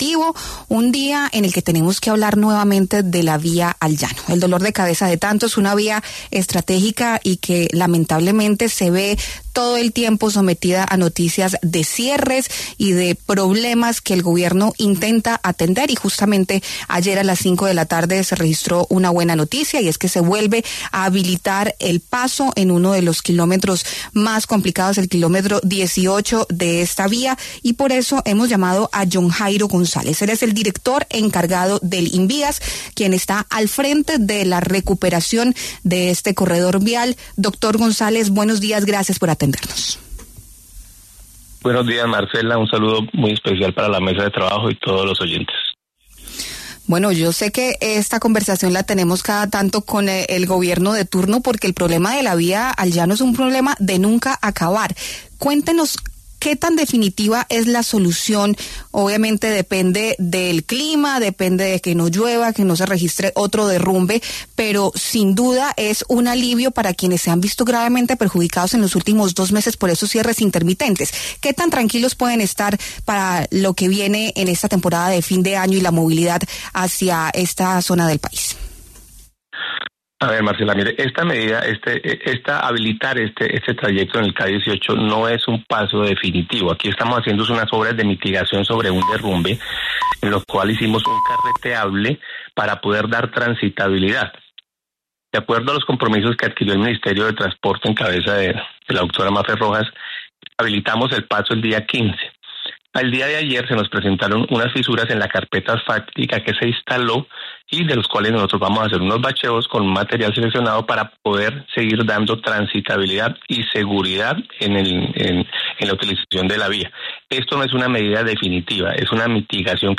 Al respecto, John Jairo González, director encargado del INVIAS, quien está al frente de la recuperación de este corredor vial, pasó por los micrófonos de La W y entregó detalles de la medida.